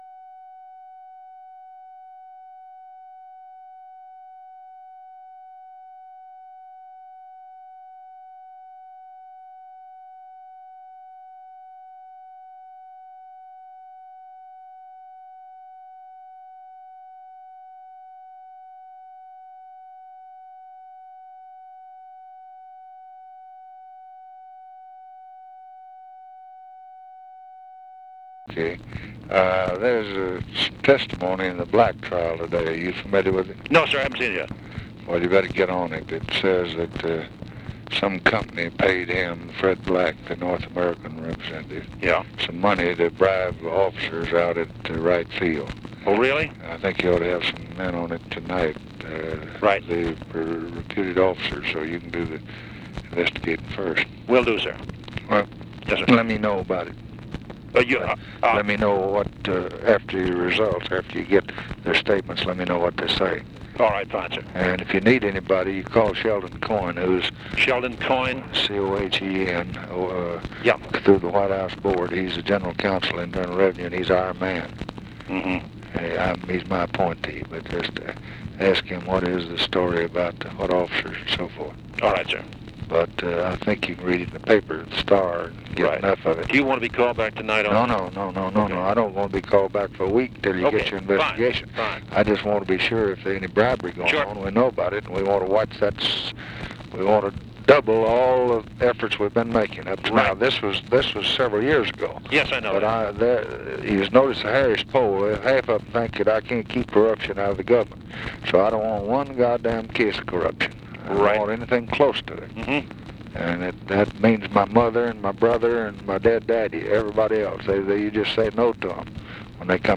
Conversation with CYRUS VANCE and OFFICE CONVERSATION, April 22, 1964
Secret White House Tapes